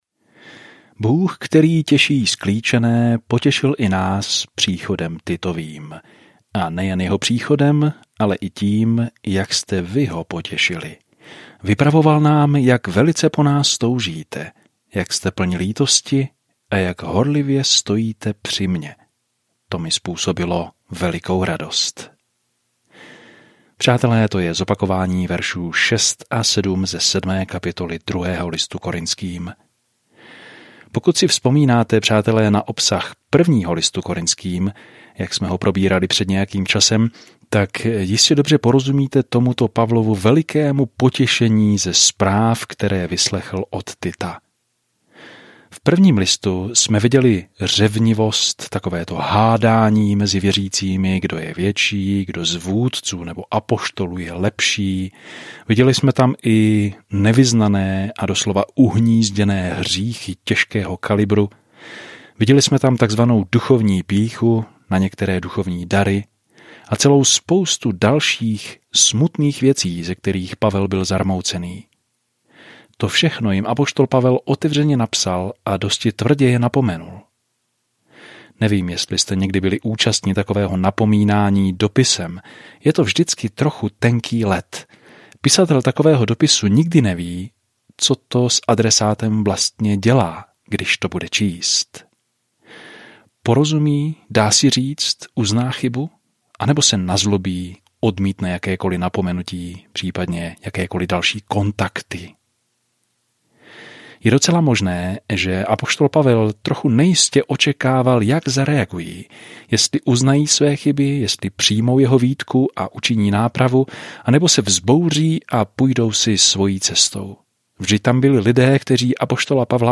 Písmo 2 Korintským 6:11-18 2 Korintským 7:1-7 Den 7 Začít tento plán Den 9 O tomto plánu Radosti ze vztahů v těle Kristově jsou zdůrazněny ve druhém dopise Korinťanům, když posloucháte audiostudii a čtete vybrané verše z Božího slova. Denně procházejte 2 Korinťany a poslouchejte audiostudii a čtěte vybrané verše z Božího slova.